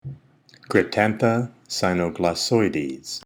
Pronunciation/Pronunciación:
Cryp-tán-tha cy-no-glos-so-ì-des